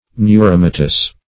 neuromatous - definition of neuromatous - synonyms, pronunciation, spelling from Free Dictionary
neuromatous.mp3